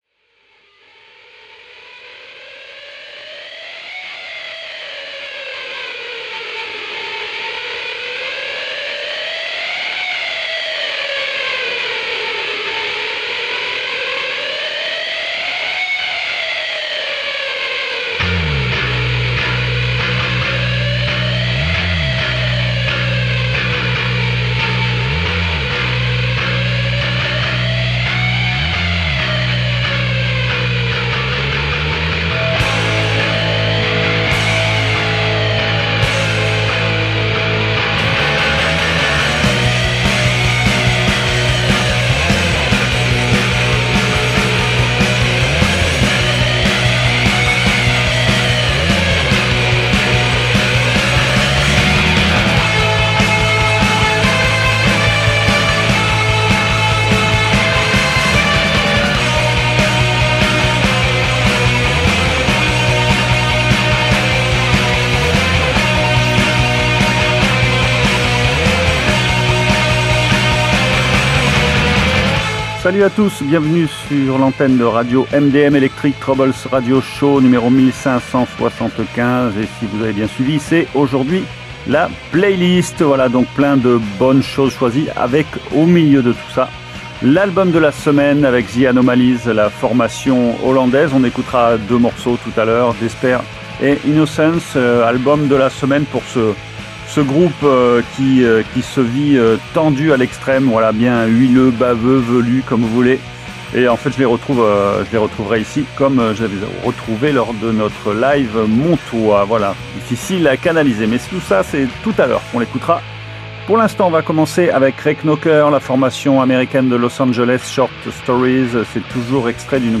Une sélection 100% fanfare